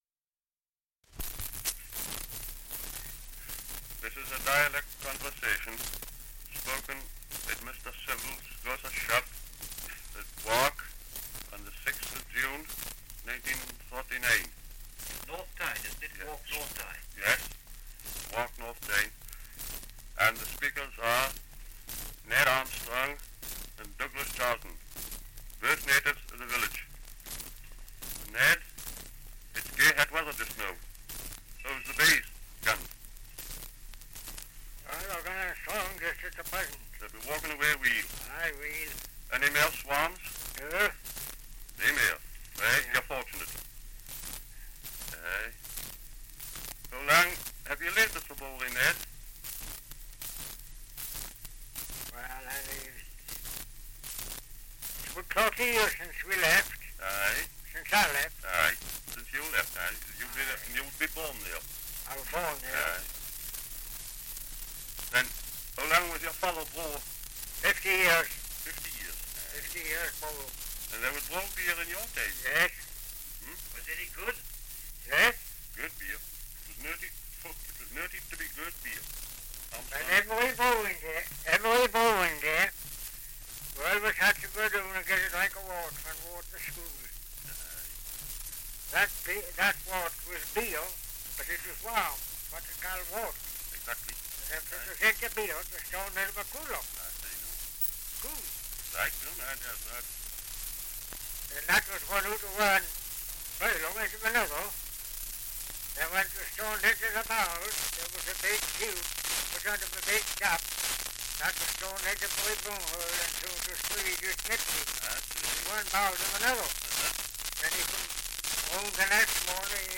Dialect recording in Wark, Northumberland
78 r.p.m., cellulose nitrate on aluminium